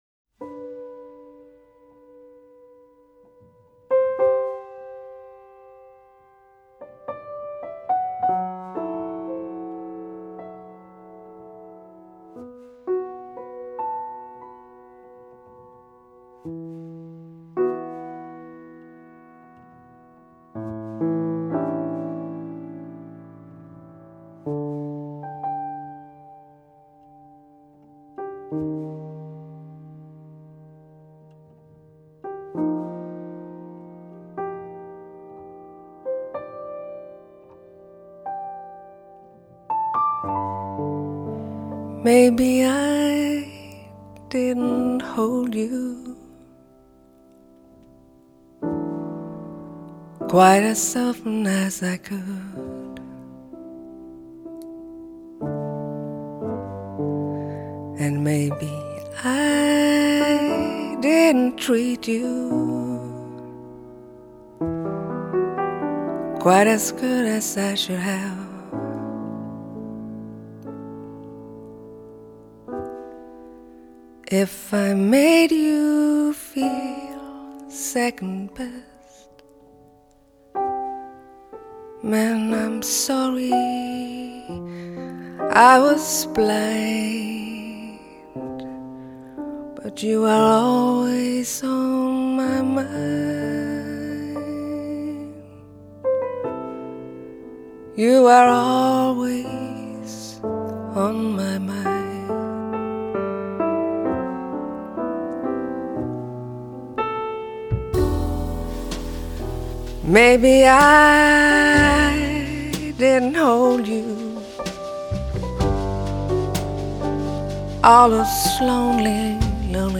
爵士及藍調 (565)
★ 來自北國挪威的清澈天籟，恬靜愜意又不失動人情感的癒療系爵士／流行天后！
★ 充滿通透感、清晰而明亮又不失夢幻感的圓潤嗓音，帶您體驗人聲最美的境地！